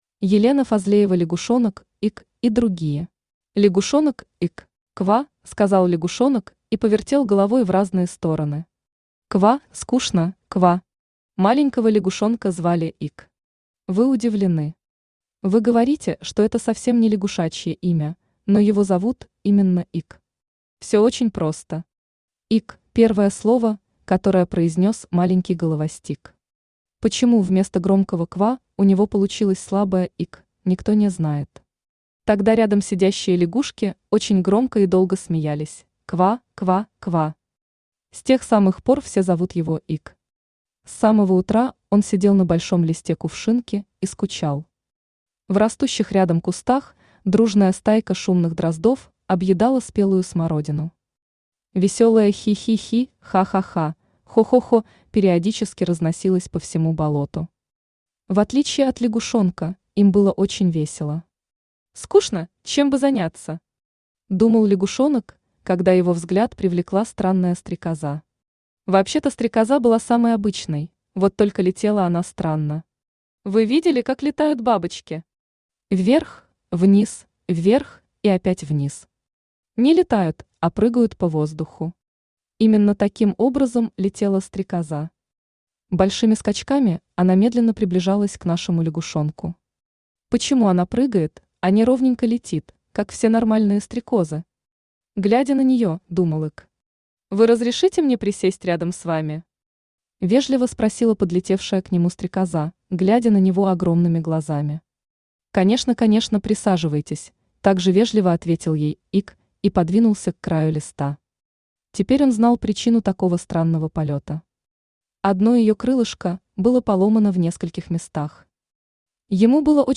Аудиокнига Лягушонок Ик и другие | Библиотека аудиокниг
Aудиокнига Лягушонок Ик и другие Автор Елена Фазлеева Читает аудиокнигу Авточтец ЛитРес.